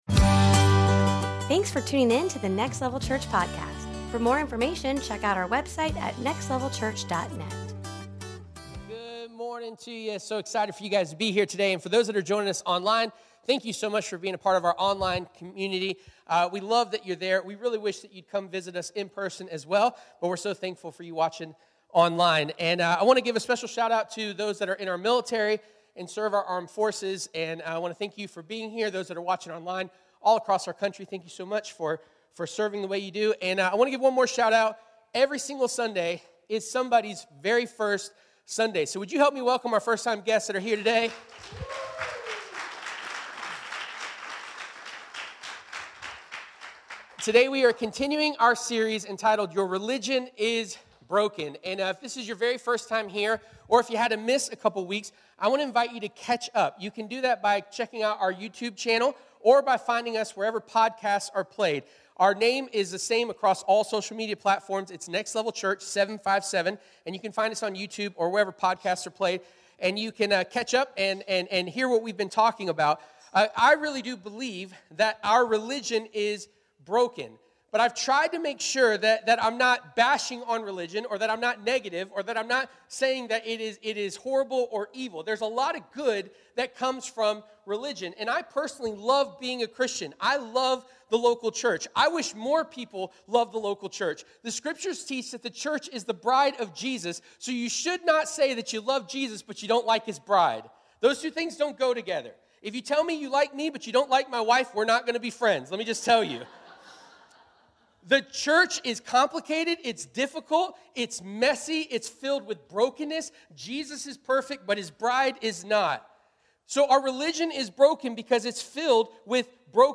Week 4 Preacher